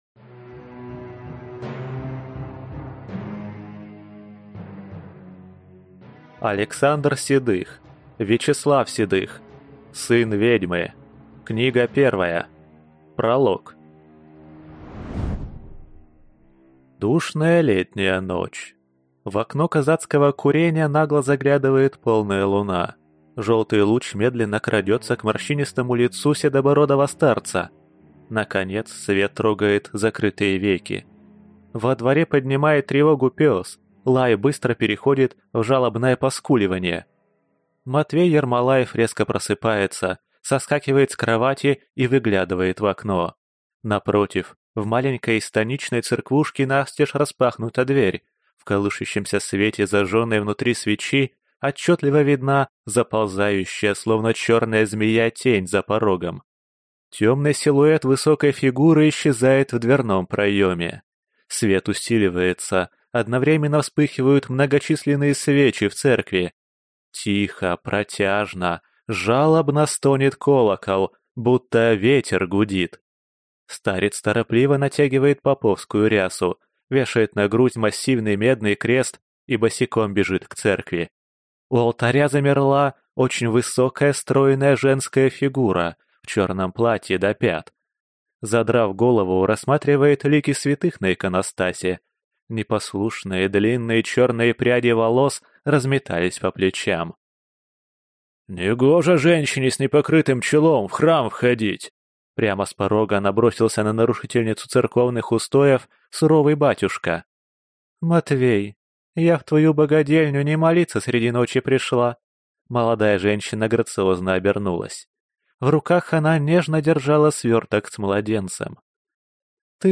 Аудиокнига Сын ведьмы | Библиотека аудиокниг